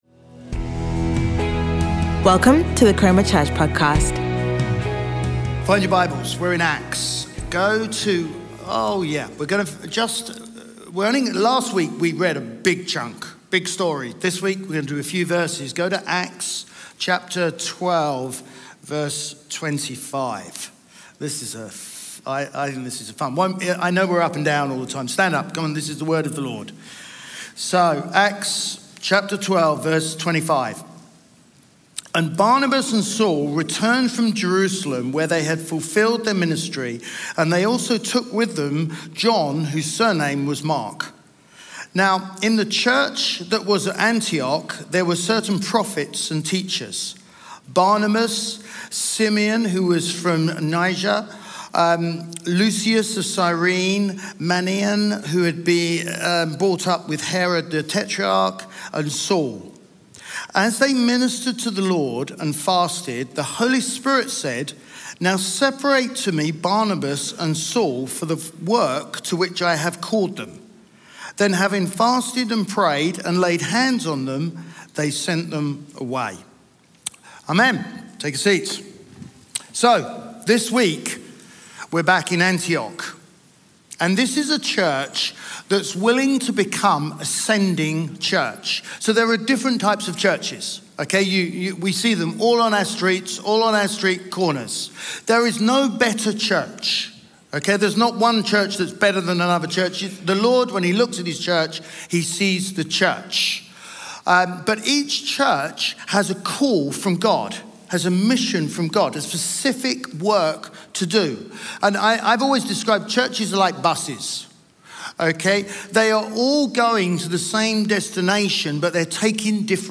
Chroma Church Live Stream
Sunday Sermon